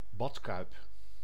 Ääntäminen
Ääntäminen : IPA: [bad.kʌjp] Tuntematon aksentti: IPA: /ˈbɑtˌkœy̯p/ Haettu sana löytyi näillä lähdekielillä: hollanti Käännös Ääninäyte Substantiivit 1. bathtub 2. bath UK US Suku: m .